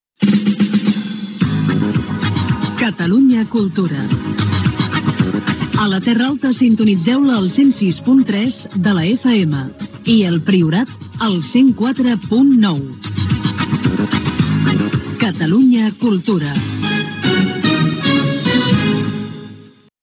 4beb9151bb31f27044e1e27f7adb1b2be2413ccc.mp3 Títol Catalunya Cultura Emissora Catalunya Cultura Cadena Catalunya Ràdio Titularitat Pública nacional Descripció Identificació amb les freqüències d'emissió a la Terra Alta i el Priorat.